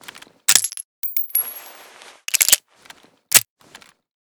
mp412_reload.ogg.bak